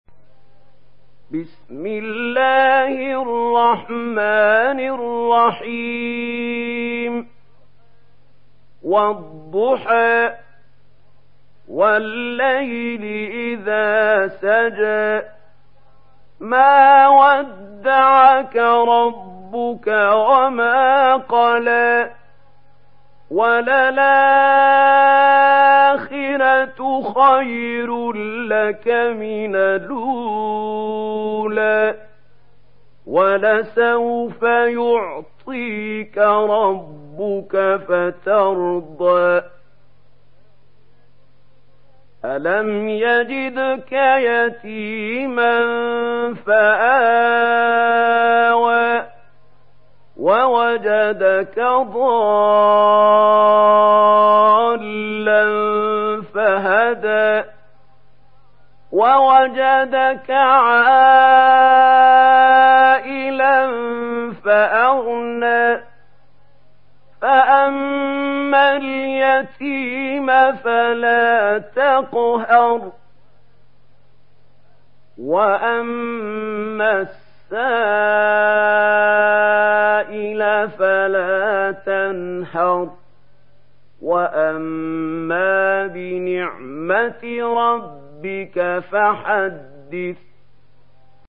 دانلود سوره الضحى mp3 محمود خليل الحصري روایت ورش از نافع, قرآن را دانلود کنید و گوش کن mp3 ، لینک مستقیم کامل